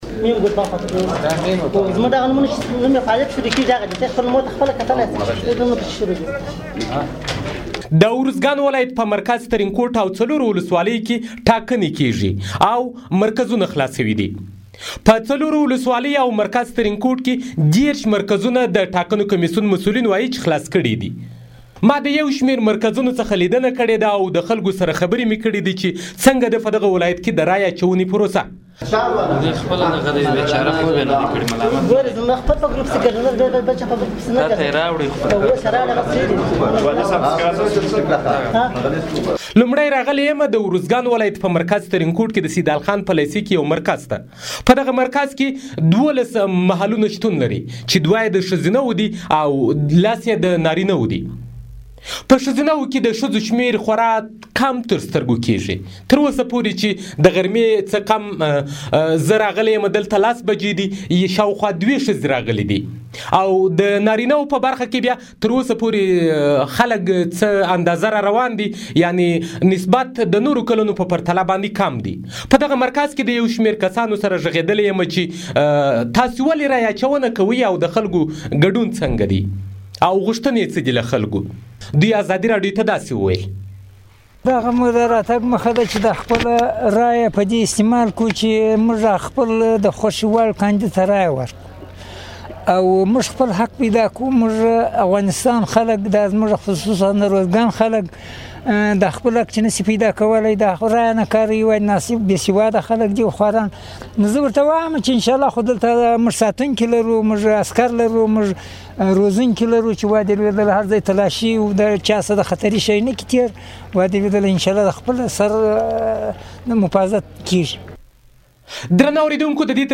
د ارزګان راپور